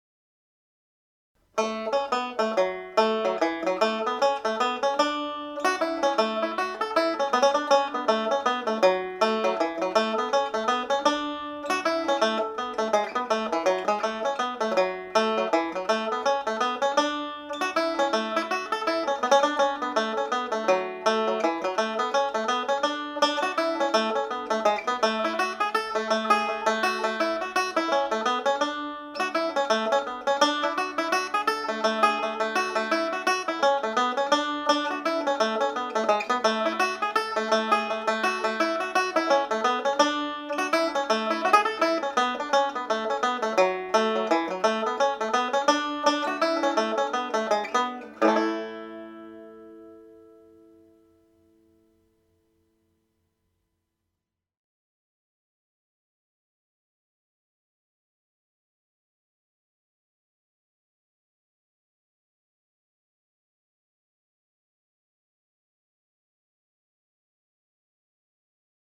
Frank’s reel has been a very popular up lifting tune in many sessions I have played in.